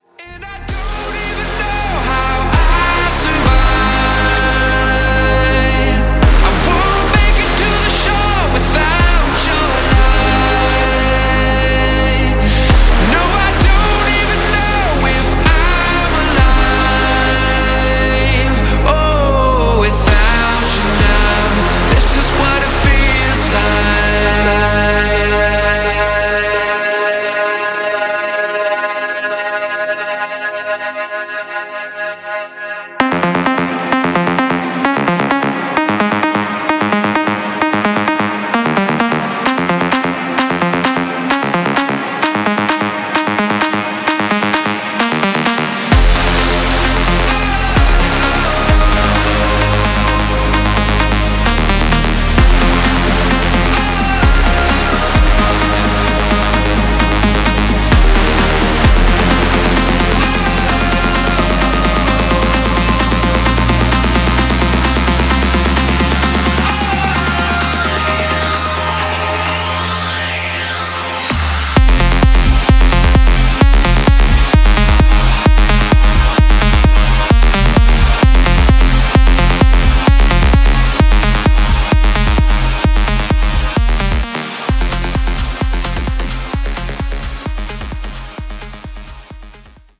Genre: LATIN
Dirty BPM: 92 Time